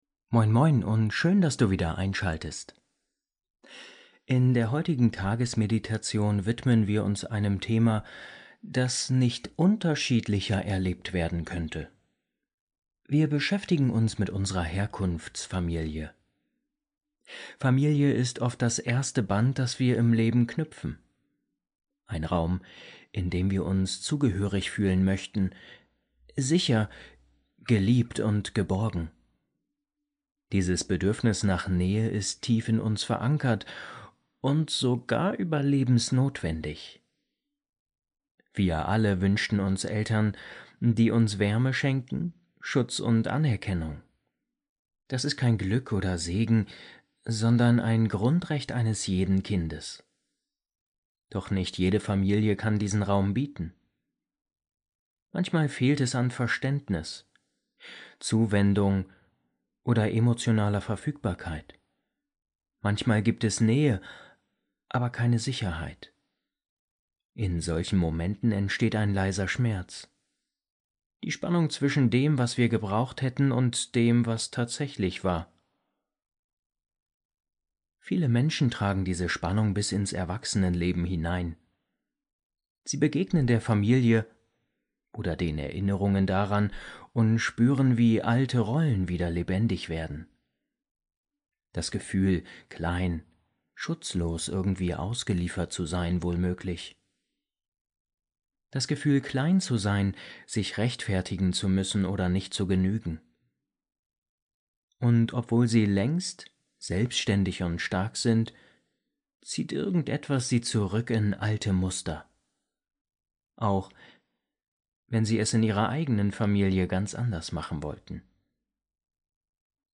Familie ist unser erster Anker – und manchmal auch unsere erste Herausforderung. Diese geführte Meditation hilft dir, das Thema Zugehörigkeit, Grenzen und Selbstfürsorge mit Achtsamkeit zu betrachten.